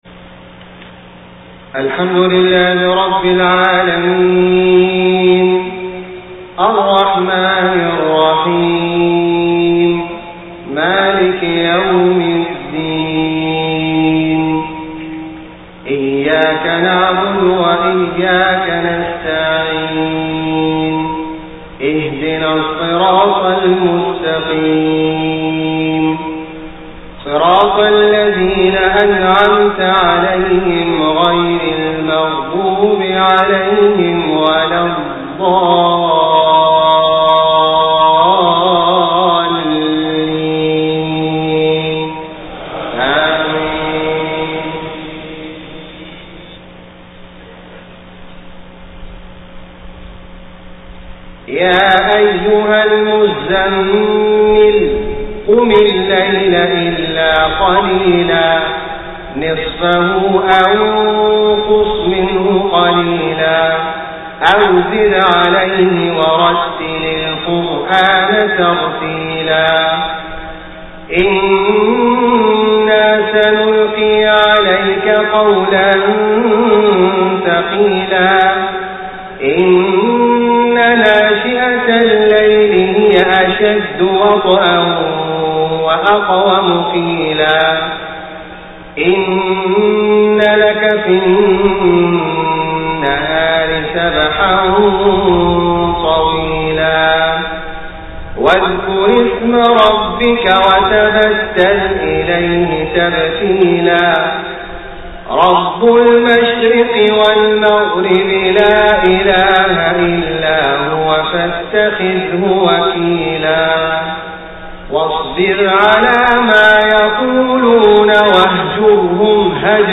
صلاة العشاء 18 صفر 1431هـ سورة المزمل كاملة > 1431 🕋 > الفروض - تلاوات الحرمين